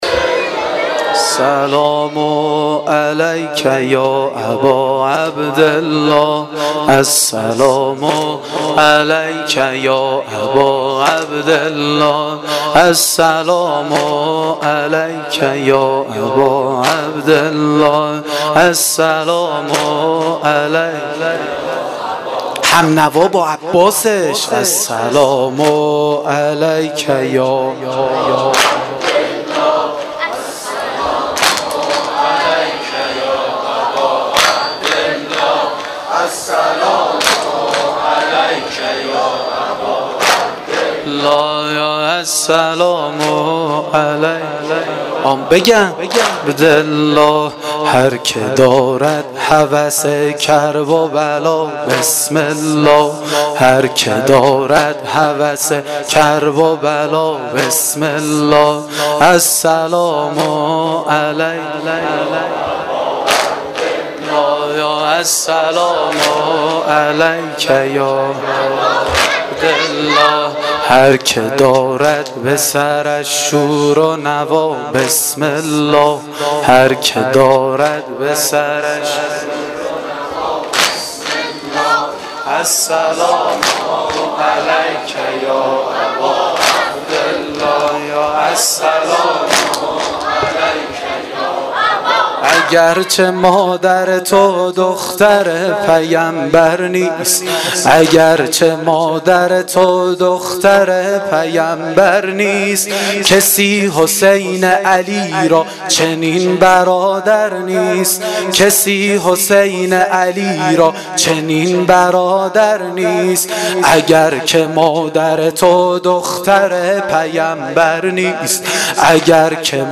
واحد شب نهم محرم